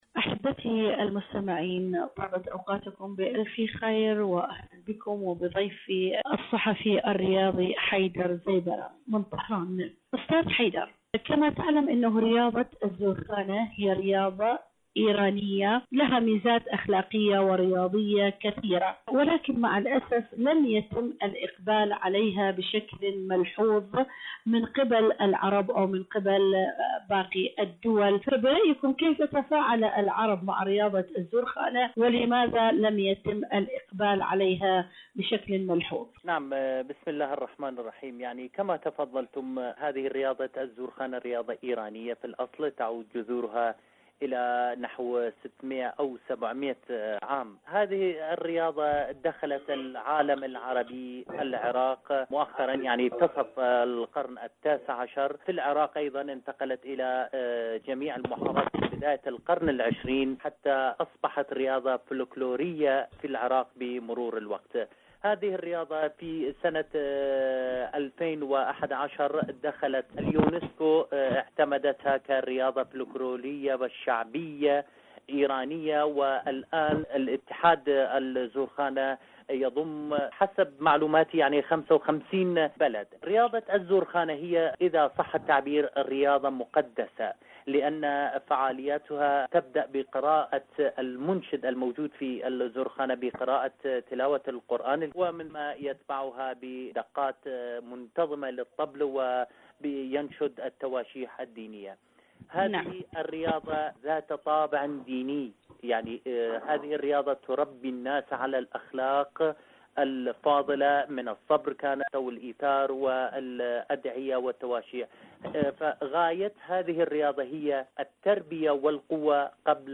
إذاعة طهران-ألوان ثقافية: مقابلة إذاعية